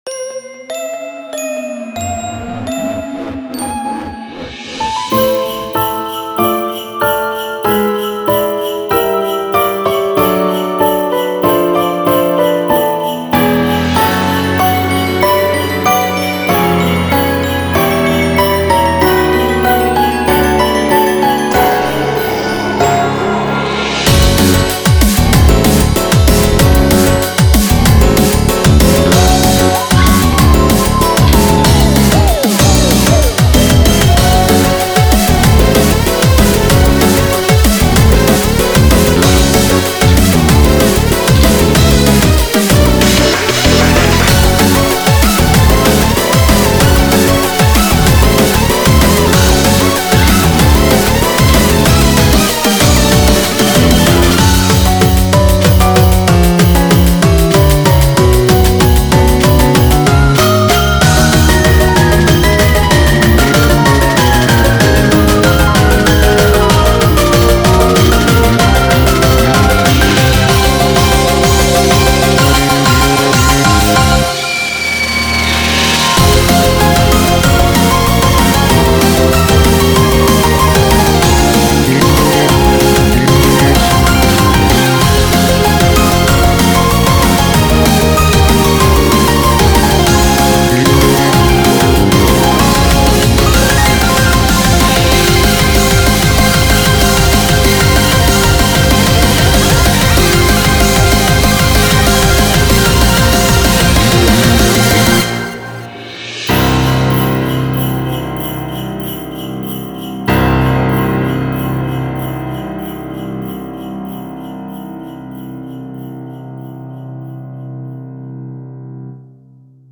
BPM48-190